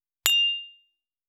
270,乾杯,アルコール,バー,お洒落,モダン,カクテルグラス,ショットグラス,おちょこ,テキーラ,シャンパングラス,カチン,チン,カン,ゴクゴク,プハー,
コップ